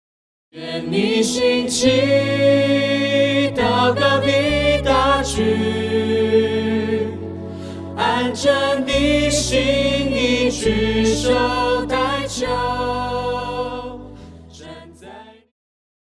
電吉他
樂團
教會音樂
演奏曲
獨奏與伴奏
有主奏
有節拍器